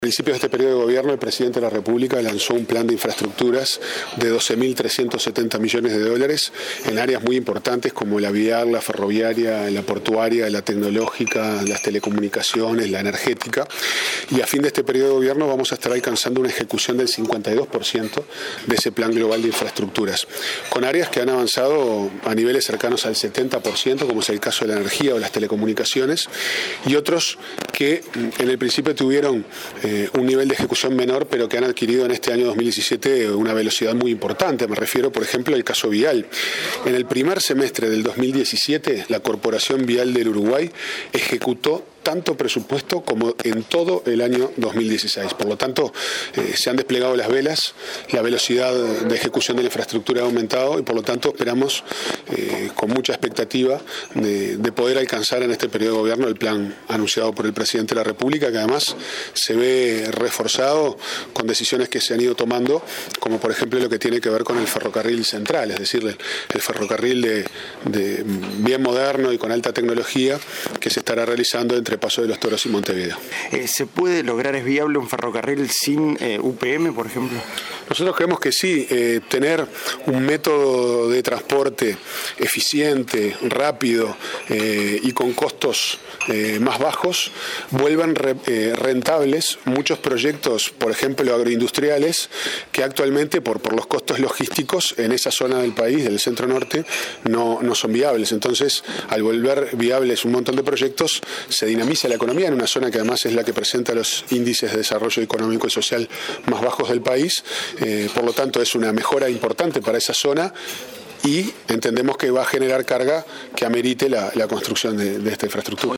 A fin de este período se habrá alcanzado un 52 % de ejecución del Plan Nacional de Infraestructura lanzado por el Gobierno al inicio de su gestión, señaló el subsecretario de Economía, Pablo Ferreri, quien agregó que en el caso de energía y telecomunicaciones el avance es de 70 %. En el primer semestre de 2017 la Corporación Vial ejecutó tanto presupuesto como en todo el 2016, añadió durante un evento empresarial de ADM.